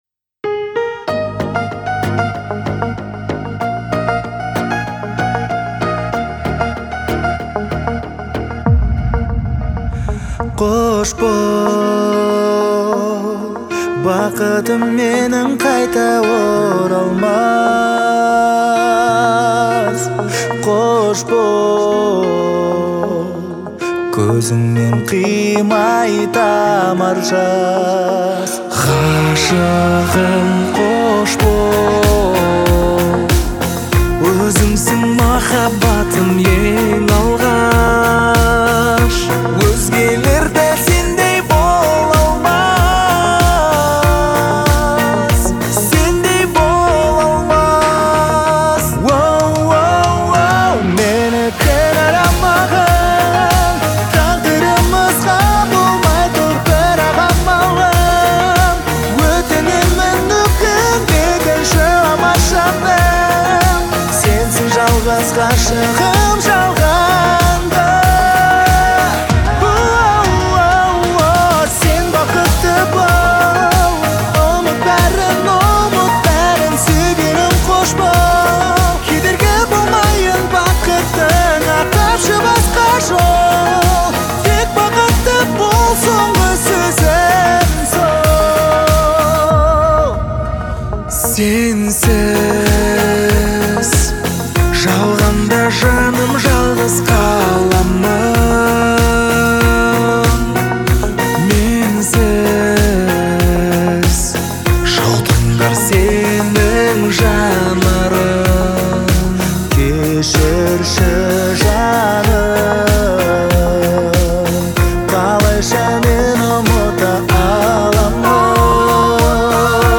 трогательный и меланхоличный трек в жанре поп